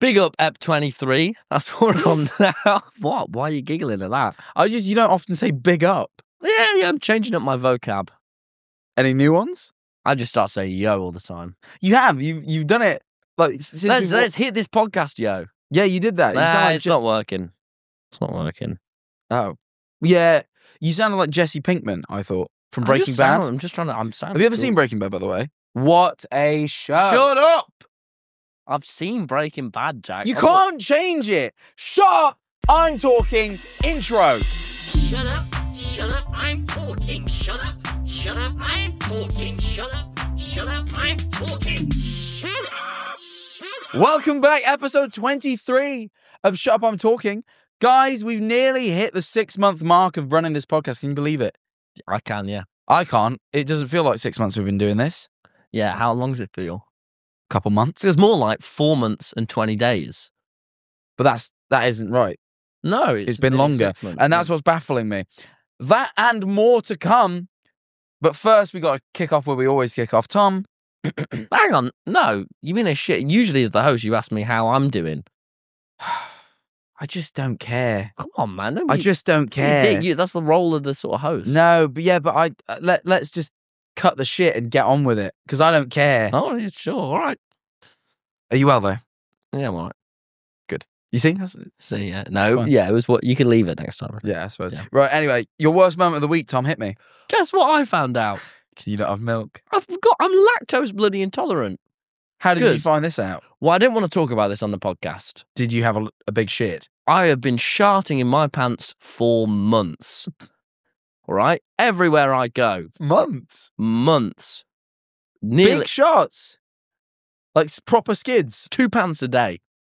Listen as they discuss mundane adult tasks, still needing their mums, and romantic gestures (or lack thereof).